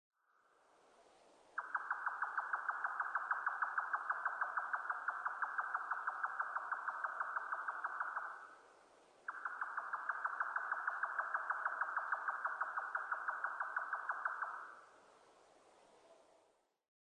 ヨタカ　Caprimulgus indicusヨタカ科
日光市稲荷川中流　alt=730m  HiFi --------------
MPEG Audio Layer3 FILE  Rec.: MARANTZ PMD670
Mic.: audio-technica AT825